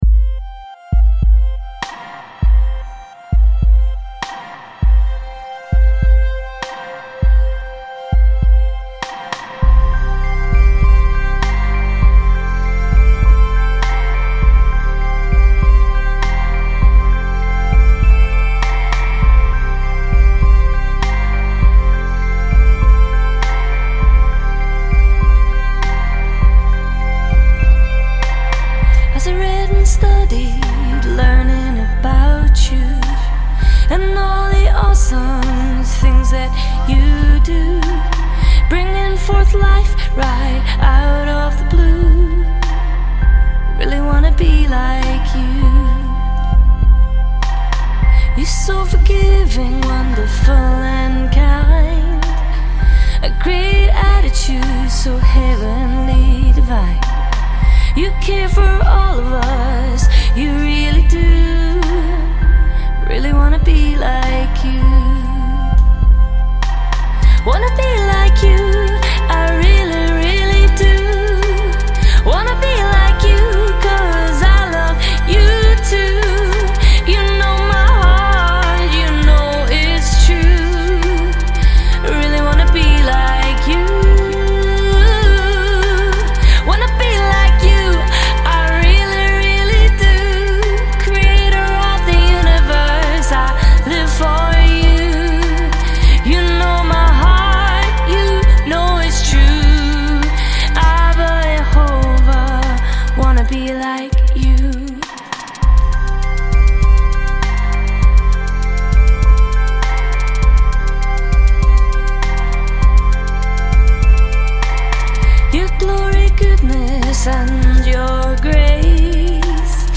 Lead & Background Vocals